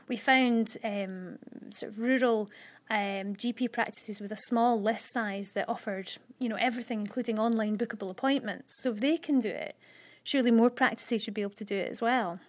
And she's telling us large practices can learn from those in rural areas: